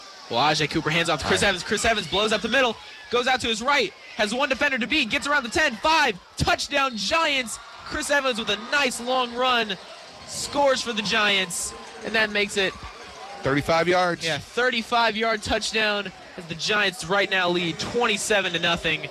WBDG's Week 1 Call of the Game